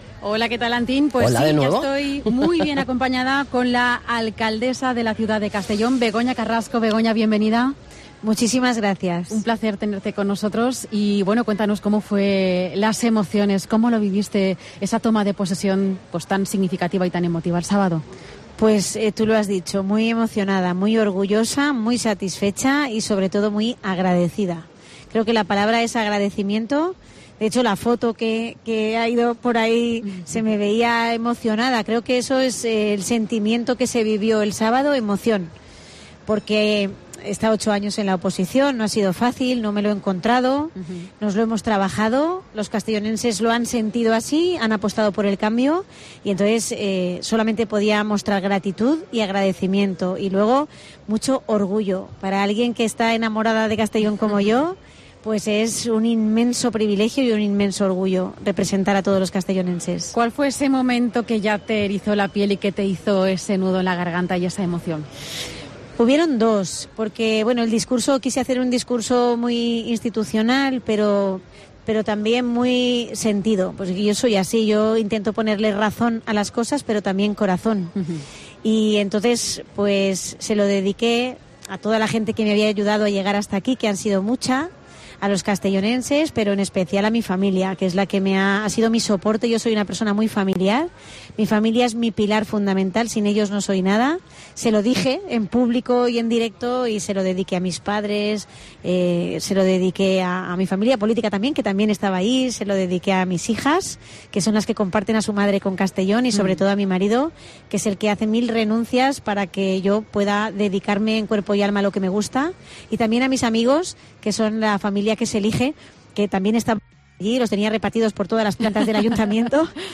La alcaldesa de Castellón, Begoña Carrasco, muestra en COPE cómo ha sido su primer día de alcaldesa de la capital de la Plana
Begoña Carrasco explica en COPE cómo ha sido su primer lunes como alcaldesa de Castellón de la Plana